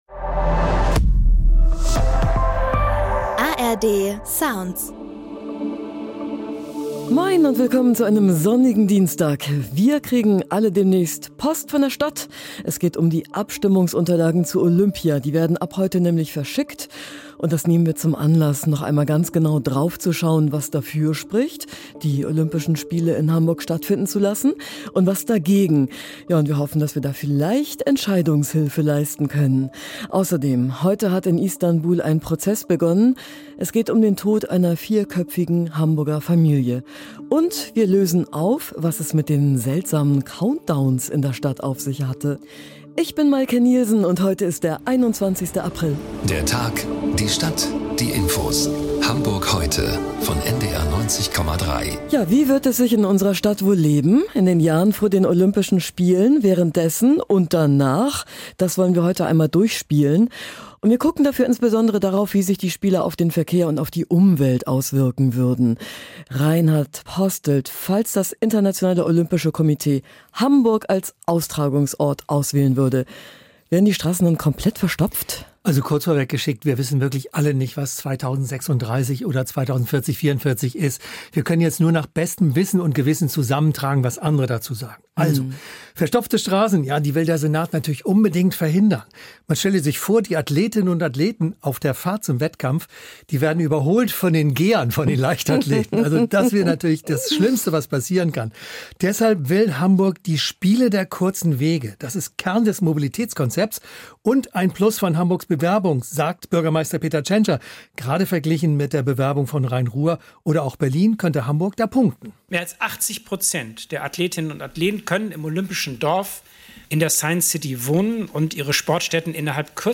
Wie sich die Olympischen Spiele auf Verkehr und Umwelt auswirken würden ~ Hamburg Heute - Nachrichten Podcast